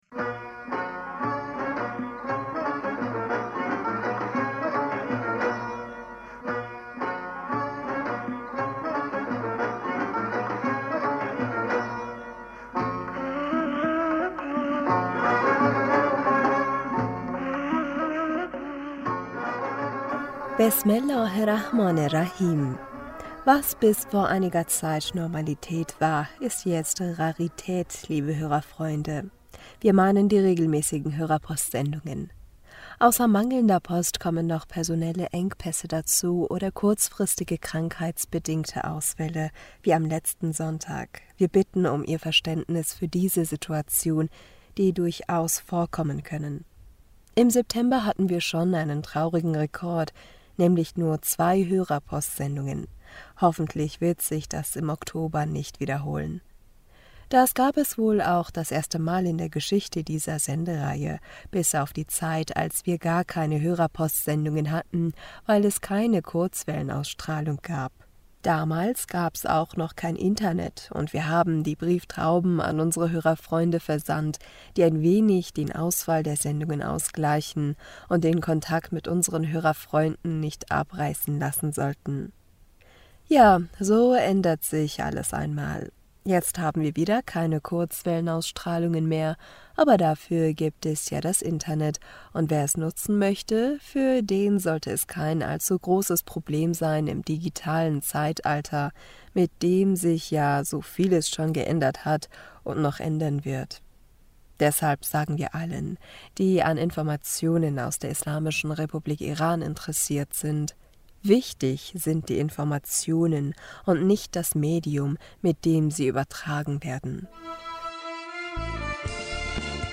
Hörerpostsendung am 09. Oktober 2022 Bismillaher rahmaner rahim - Was bis vor einiger Zeit Normalität war, ist jetzt Rarität liebe Hörerfreunde - wir mein...